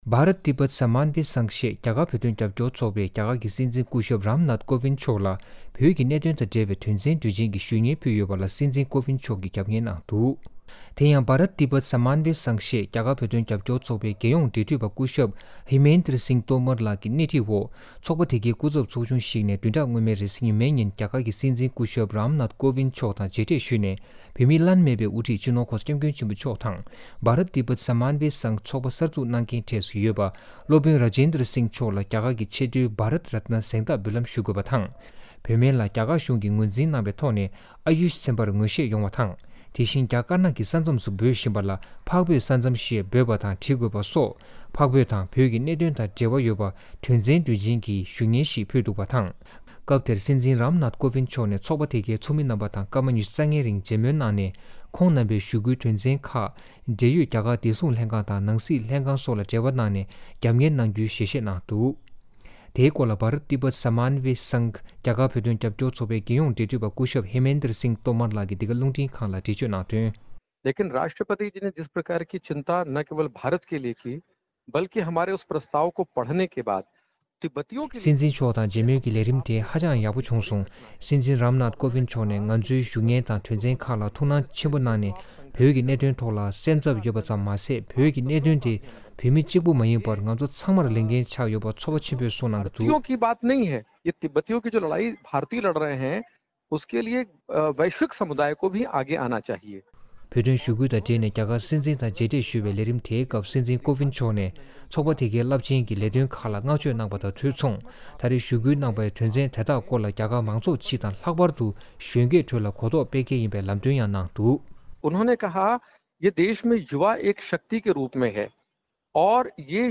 ལྡི་ལི་ནས་འདི་གའི་གསར་འགོད་པ